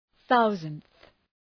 Προφορά
{‘ɵaʋzəndɵ}